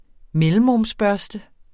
Udtale [ ˈmεləmʁɔms- ]